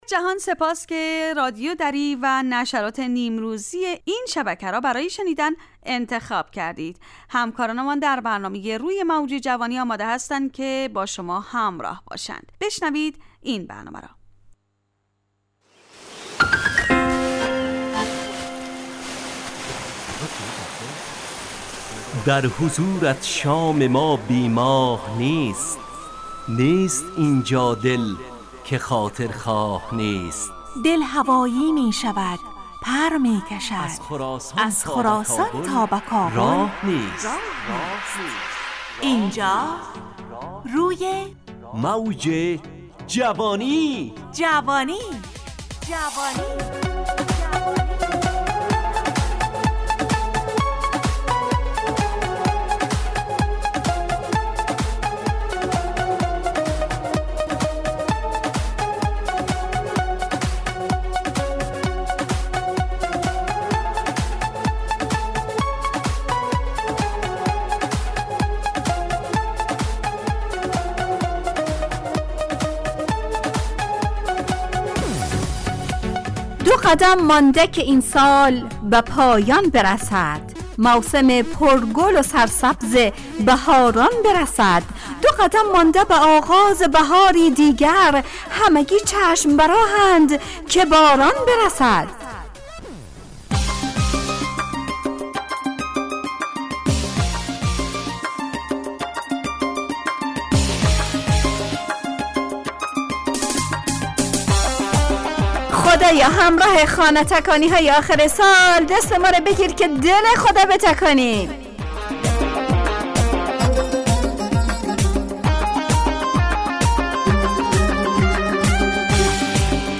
همراه با ترانه و موسیقی مدت برنامه 55 دقیقه .